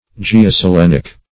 Search Result for " geoselenic" : The Collaborative International Dictionary of English v.0.48: Geoselenic \Ge`o*se*len"ic\, a. [Gr. ge`a, gh^, the earth + ? moon.] Pertaining to the earth and moon; belonging to the joint action or mutual relations of the earth and moon; as, geoselenic phenomena.